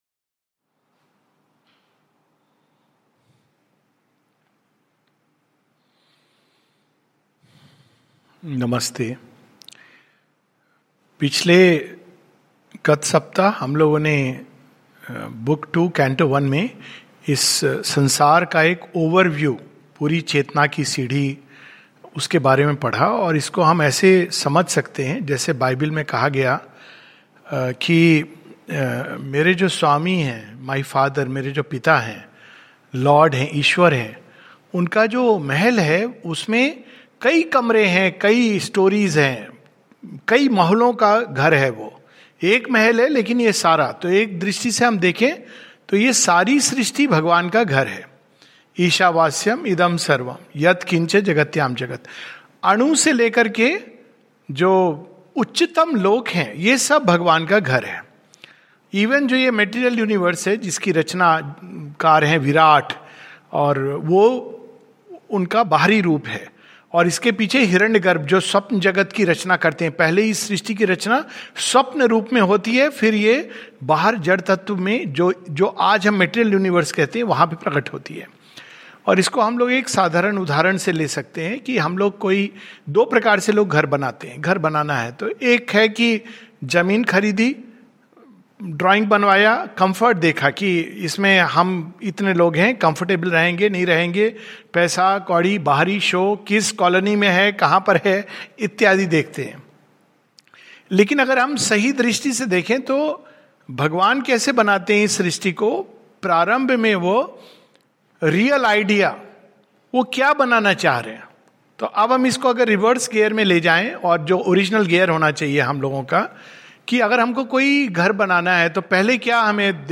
Savitri in Hindi